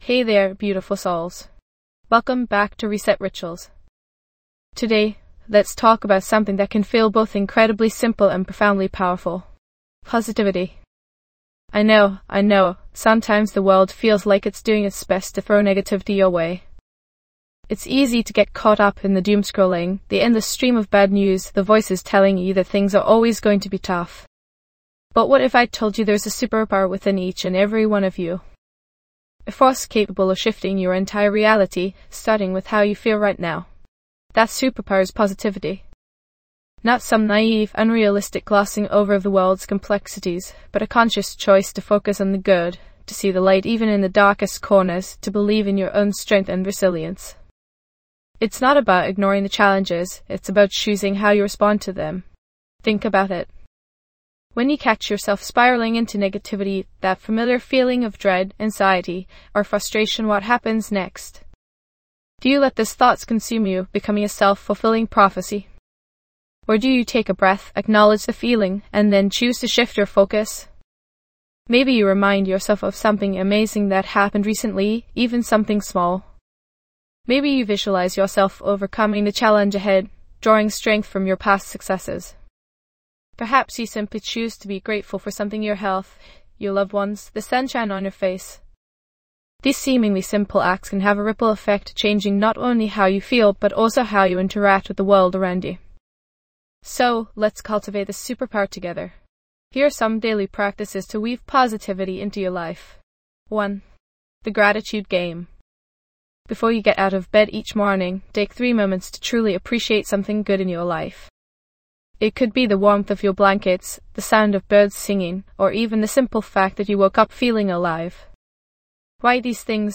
Immerse yourself in a soothing guided meditation that will help you cultivate a positive mindset, banish negative thoughts, and attract abundance and success into your life.